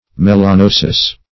Melanosis \Mel`a*no"sis\, [NL., fr. Gr. ? a growing black, fr.